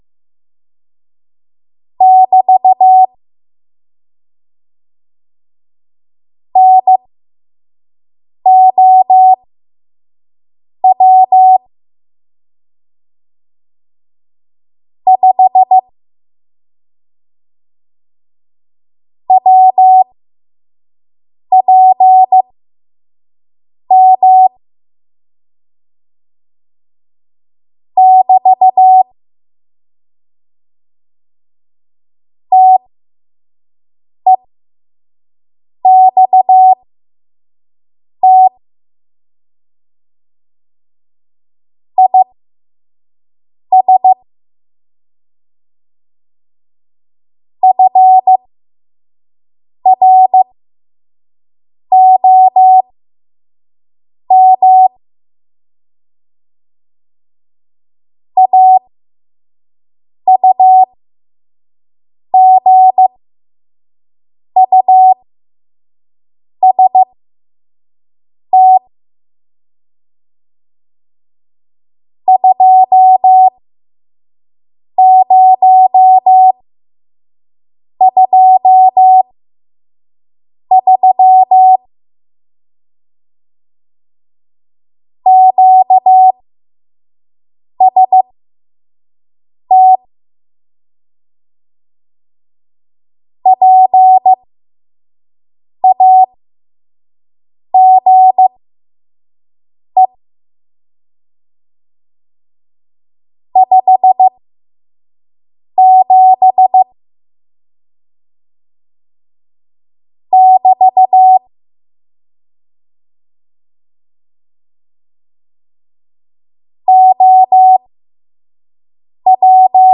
5 WPM Code Archive